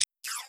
UI_MenuClose.wav